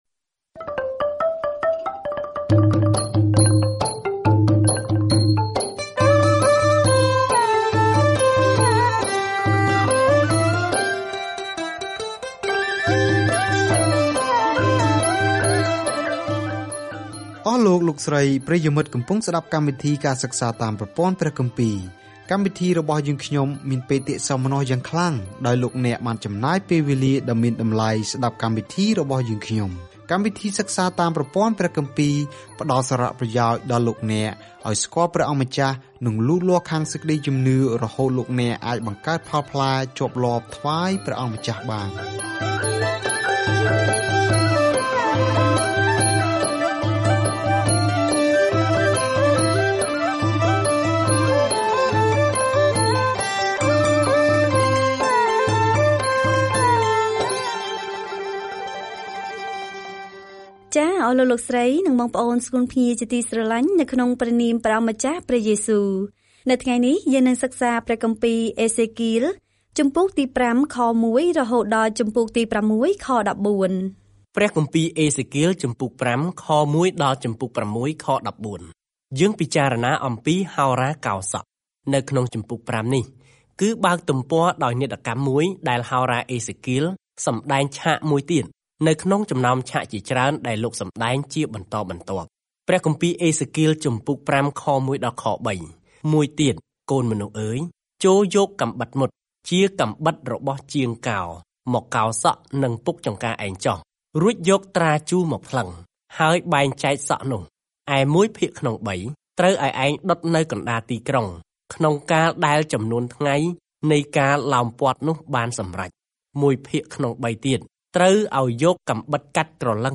ប្រជាជននឹងមិនស្តាប់ពាក្យព្រមានរបស់អេសេគាលឲ្យត្រឡប់ទៅរកព្រះវិញទេ ដូច្នេះ ផ្ទុយទៅវិញគាត់បានបញ្ចេញពាក្យប្រស្នាដ៏អាក្រក់ ហើយវាបានចាក់ទម្លុះដួងចិត្តមនុស្ស។ ការធ្វើដំណើរប្រចាំថ្ងៃតាមរយៈអេសេគាល ពេលអ្នកស្តាប់ការសិក្សាជាសំឡេង ហើយអានខគម្ពីរដែលជ្រើសរើសពីព្រះបន្ទូលរបស់ព្រះ។